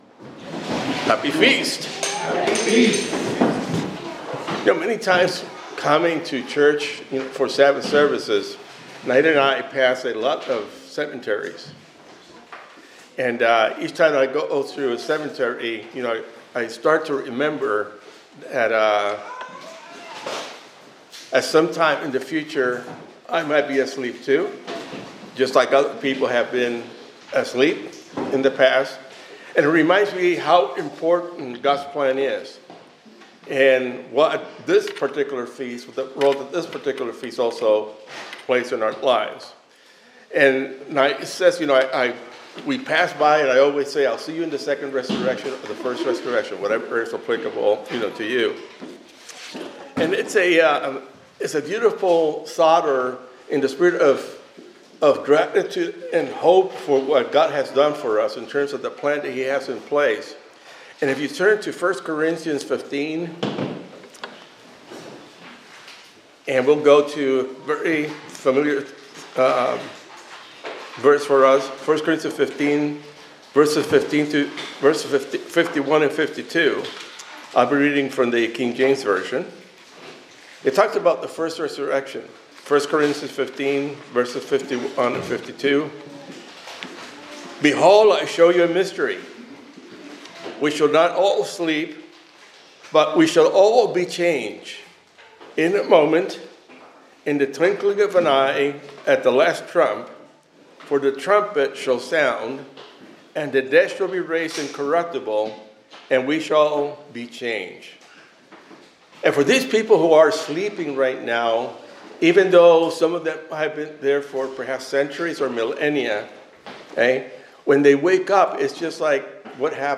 The sermon reflects on the significance of the Feast of Trumpets, emphasizing hope, gratitude, and the certainty of resurrection as part of God's plan. It highlights the transformative power of resurrection and the loving character of God, encouraging celebration and thankfulness for divine promises and presence.
Given in Hartford, CT